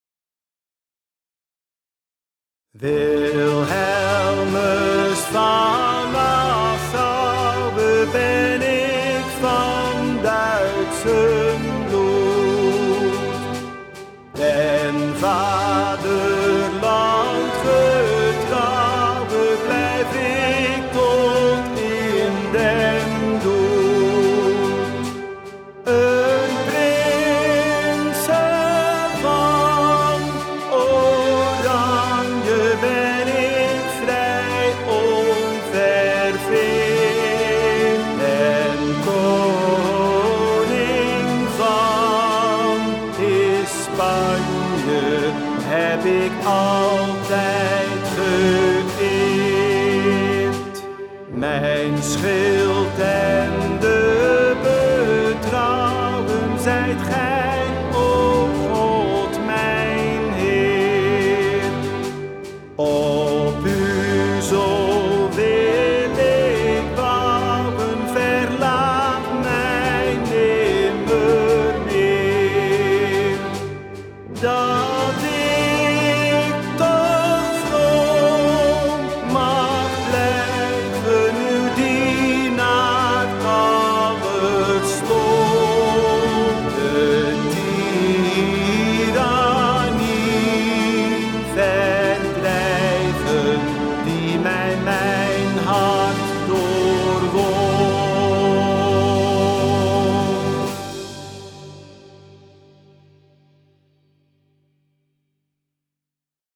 Nationaal_volkslied_Wilhelmus_van_NassouweAangepaste_begeleidingstrack.mp3